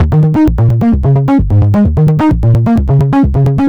Frantic Bass Eb 130.wav